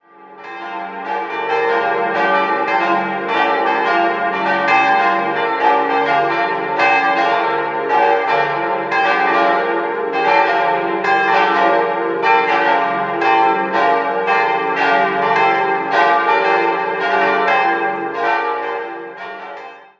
Annaglocke d'-5 1.300 kg 1.290 mm 1505
Evangelistenglocke f'+2 1.000 kg 1.167 mm 1399
Helenaglocke g'+3 700 kg 1.040 mm 1958
Heilige-Familie-Glocke a'+9 460 kg 900 mm 1926